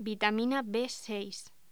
Locución: Vitamina B6
voz